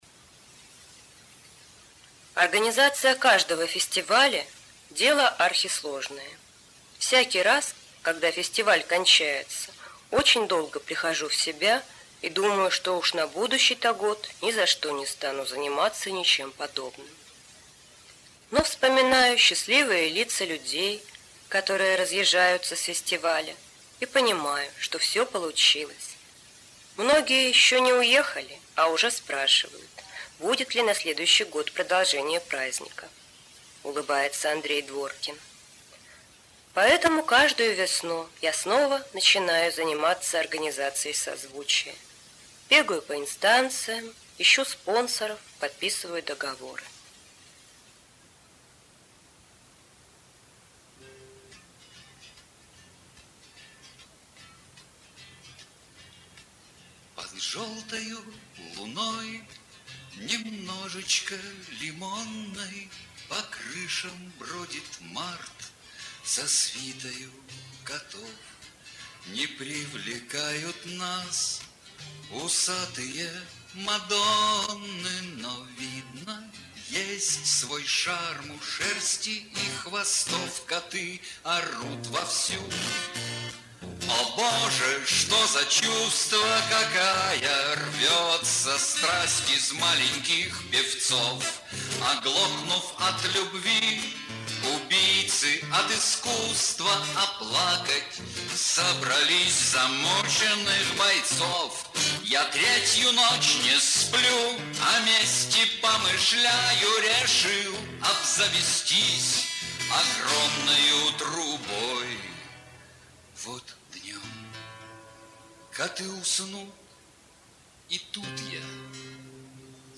Жизненный и музыкальный пути. Музыкальное содержание - оригинальный авторский диск.